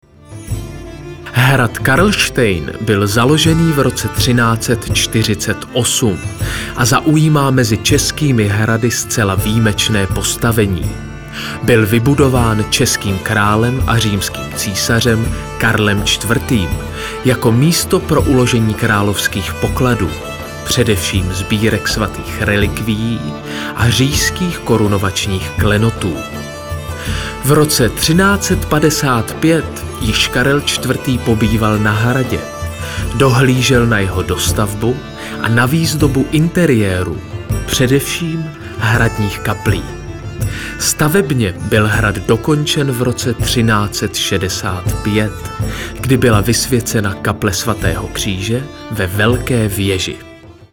Ukázka 01 – Komentář k dokumentárnímu filmu
ukazka-01-Dokumentární-film.mp3